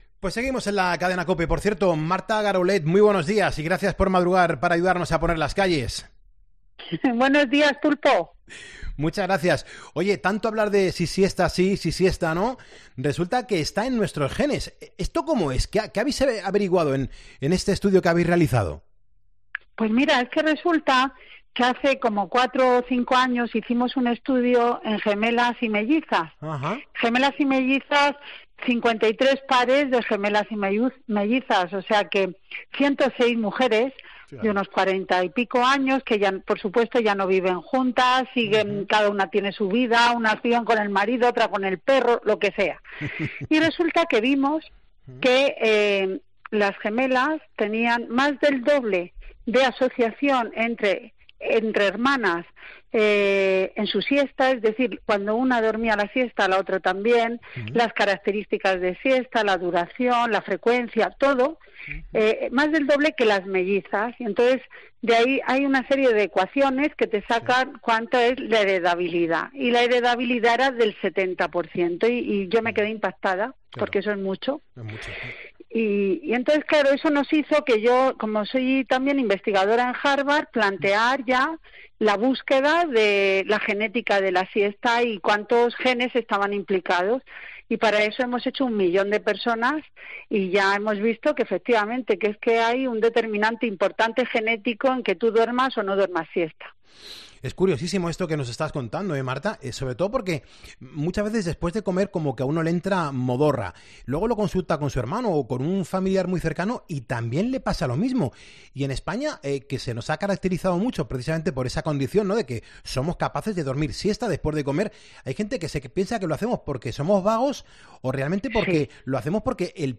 Entrevistas en Poniendo las calles Si eres de echarte la siesta la culpa es de tus genes ¿Eres de echarte la siesta?